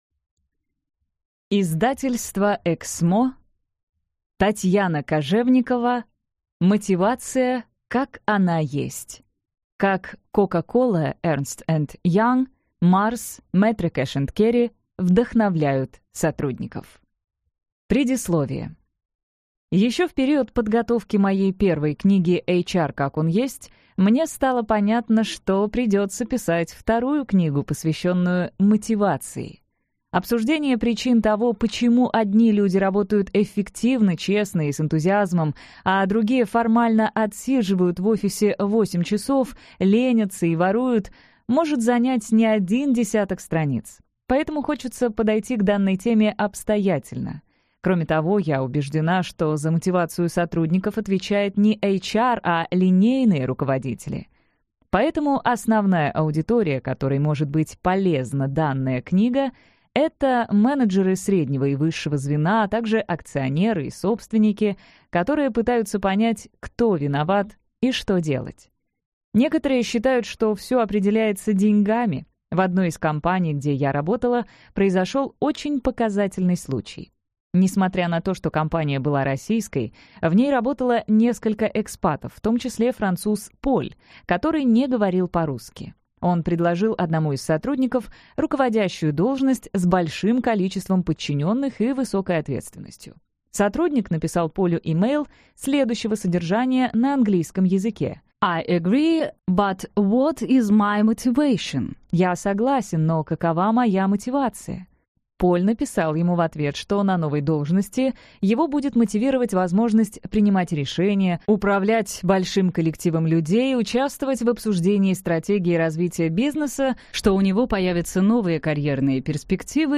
Аудиокнига Мотивация как она есть. Как Coca-Cola, Ernst & Young, MARS, METRO Cash & Carry вдохновляют сотрудников | Библиотека аудиокниг